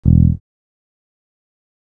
E THUMB 2.wav